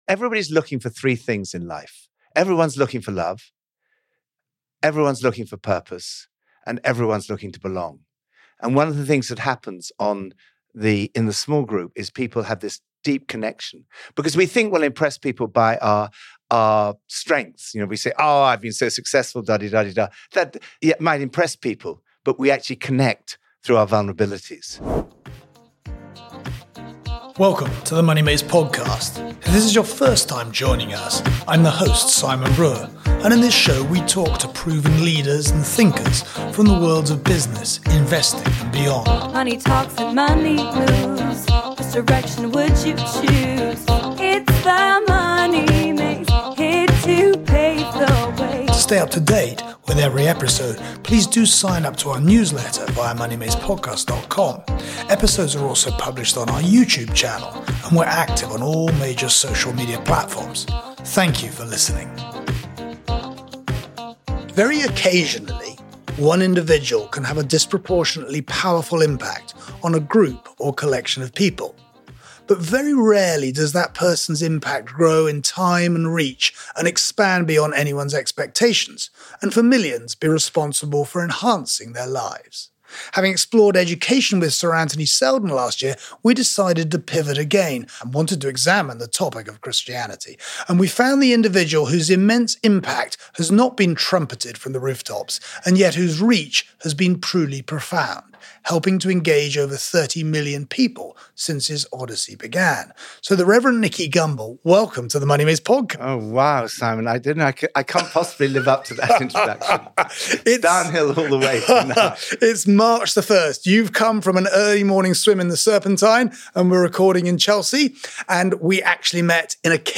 In this interview he explains his own pivot to faith, how he was asked to take charge of the Alpha Course development, and in the subsequent years how its reach was extended globally. He explains how he built it into a global brand, how charismatic leadership can be relevant to all fields, the changing relationship between faith and secularism, the role of religion for prisoner rehabilitation, as well as thoughts on morality, money and giving!